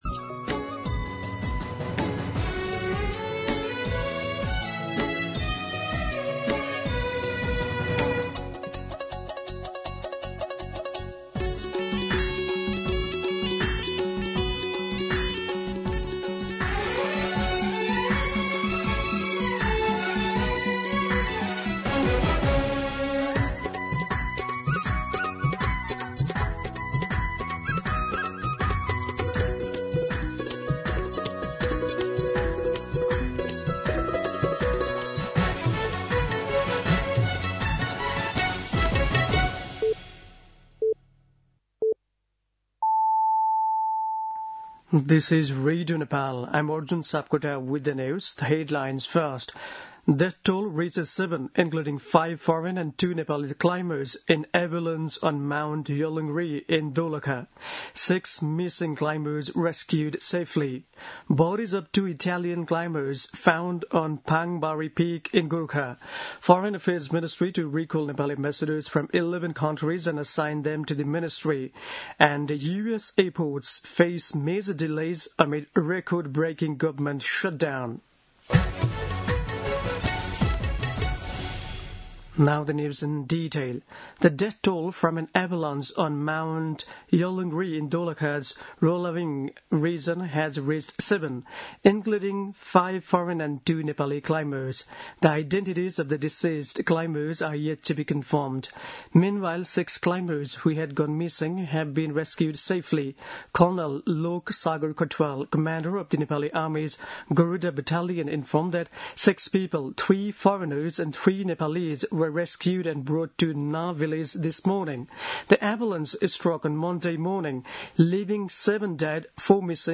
दिउँसो २ बजेको अङ्ग्रेजी समाचार : १८ कार्तिक , २०८२